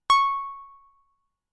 Roland Juno 6 PW Pluck " Roland Juno 6 PW Pluck C6 (PW Pluck85127)
Tag: CSharp6 MIDI音符-85 罗兰朱诺-6 合成器 单票据 多重采样